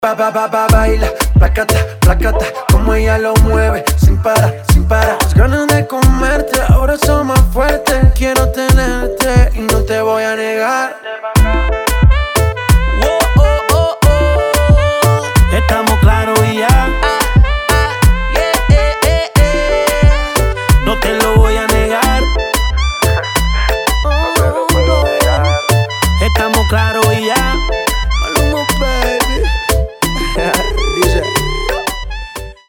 Танцевальные рингтоны
Заводные
Реггетон